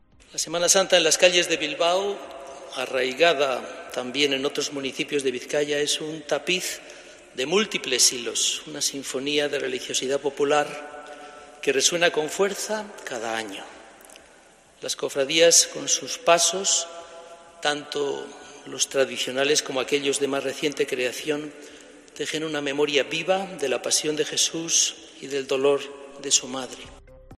El Obispo de Bilbao, Monseñor Joseba Segura, pronuncia en la Catedral de Santiago el pregón de la Semana Santa de Bilbao